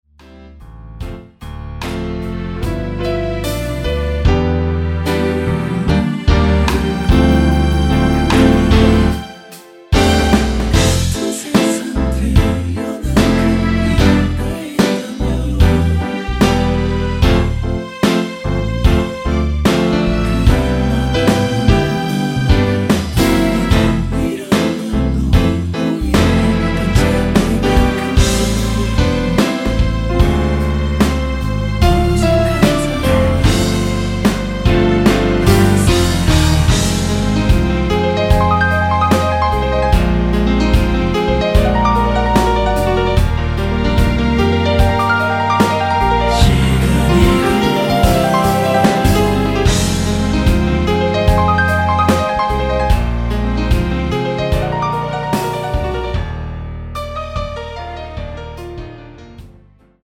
(-2) 내린 코러스 포함된 MR 입니다.(미리듣기 참조)
앞부분30초, 뒷부분30초씩 편집해서 올려 드리고 있습니다.
중간에 음이 끈어지고 다시 나오는 이유는